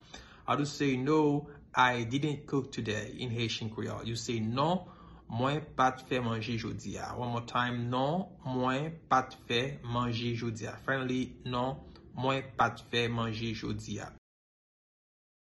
Pronunciation:
No-I-didnt-cook-today-in-Haitian-Creole-Non-mwen-pa-t-fe-manje-jodi-a-pronunciation.mp3